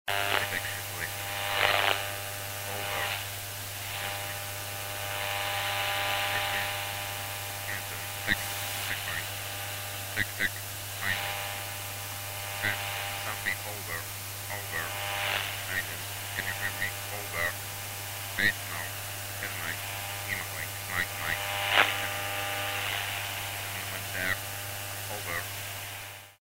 Звуки радиосигналов
Сквозь шум пробиваются голоса